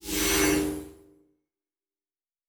pgs/Assets/Audio/Sci-Fi Sounds/Doors and Portals/Teleport 3_2.wav at master
Teleport 3_2.wav